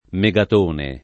vai all'elenco alfabetico delle voci ingrandisci il carattere 100% rimpicciolisci il carattere stampa invia tramite posta elettronica codividi su Facebook megatone [ me g at 1 ne ] o megaton [ m $g aton ] s. m. (fis.)